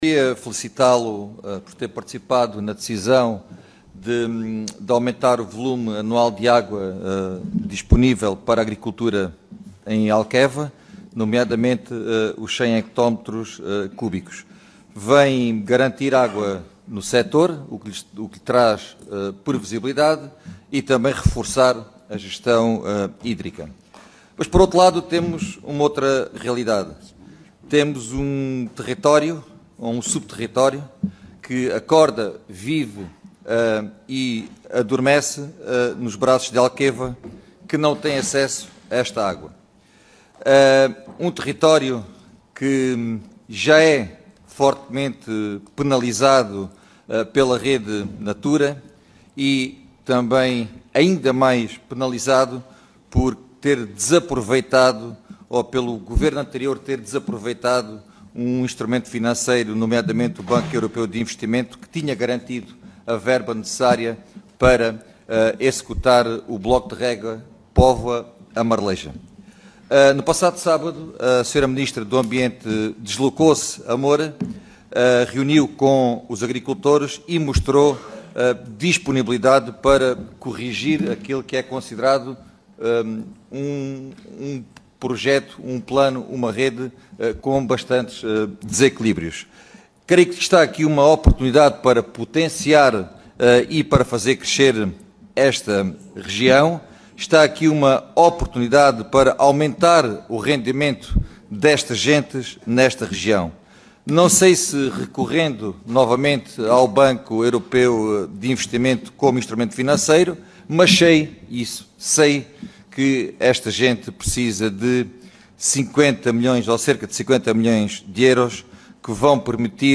Em vídeo publicado na sua página oficial de Facebook, Gonçalo Valente, na sua intervenção na Comissão de Agricultura e Pescas, recorda que a ministra do Ambiente Maria da Graça Carvalho esteve em Moura, no passado sábado, onde “mostrou disponibilidade para corrigir este projeto com bastantes desequilíbrios”.